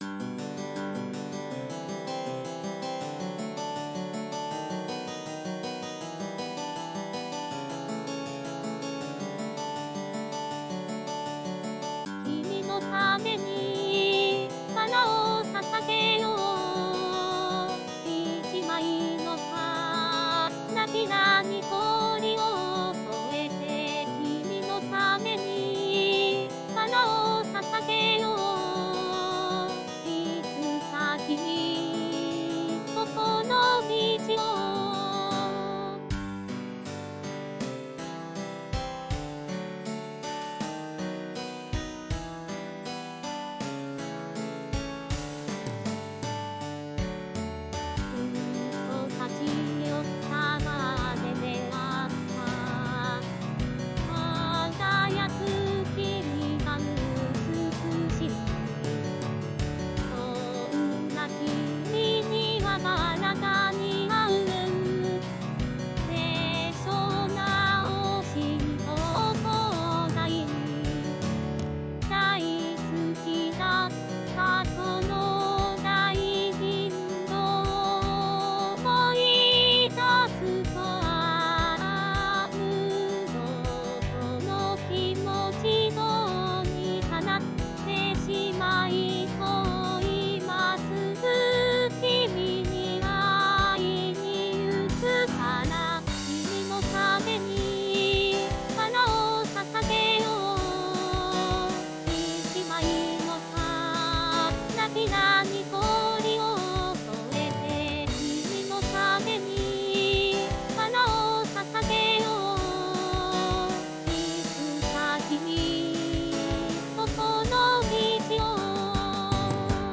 MPEG ADTS, layer III, v2, 128 kbps, 16 kHz, Monaural